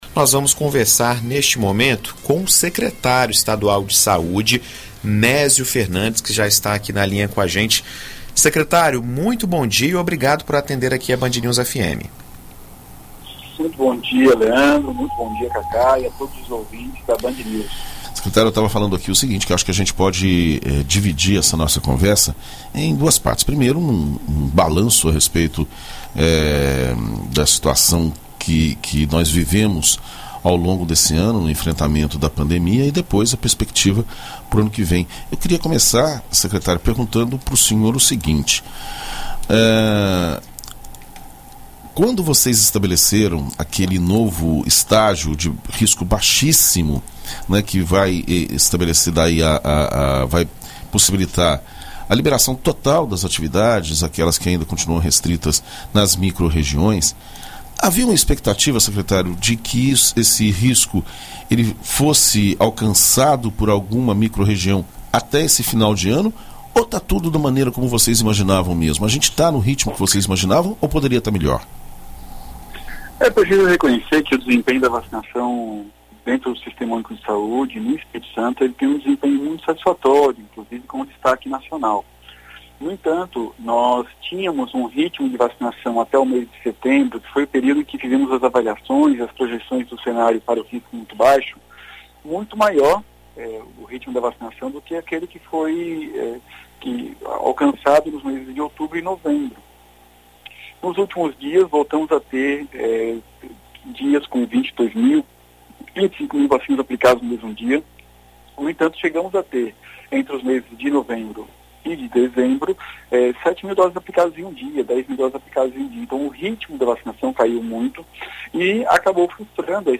O secretário de Estado da Saúde, Nésio Fernandes, faz um balanço sobre as medidas adotadas para conter a disseminação da doença.